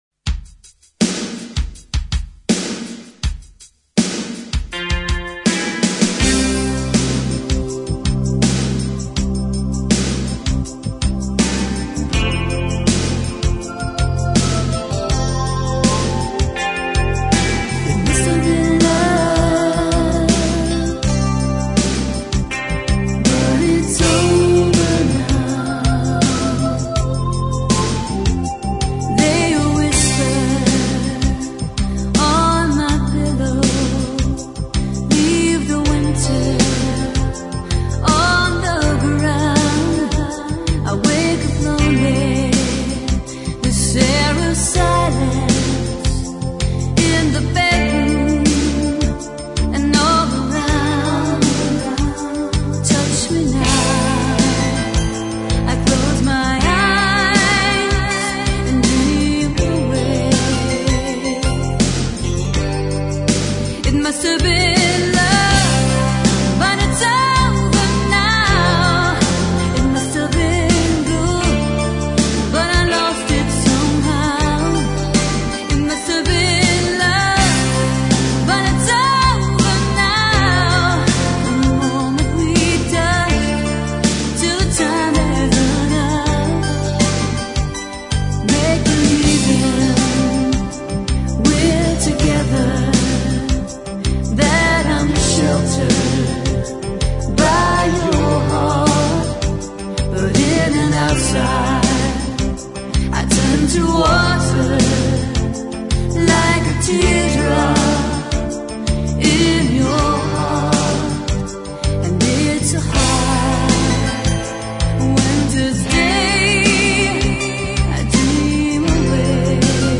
90s Dance Music